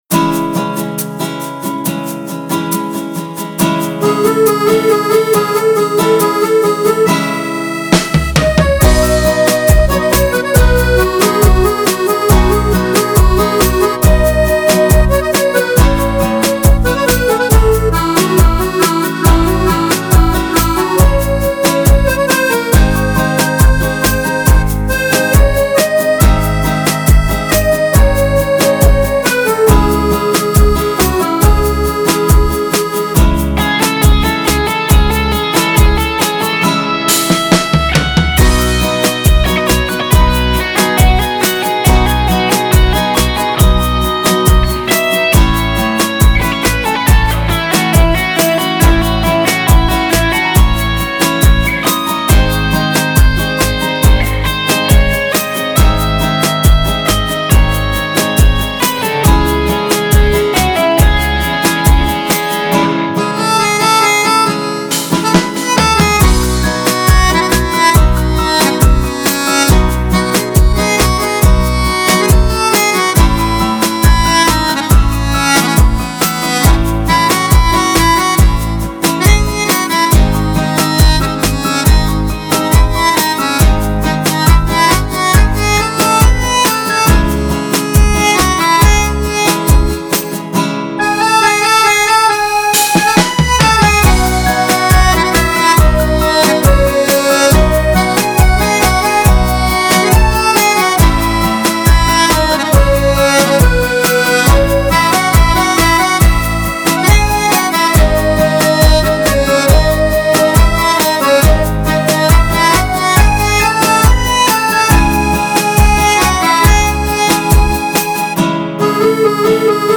موسیقی بی کلام
ملودیکا / گیتار / آکاردئون / پیانو